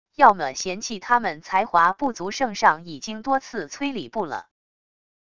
要么嫌弃他们才华不足圣上已经多次催礼部了wav音频生成系统WAV Audio Player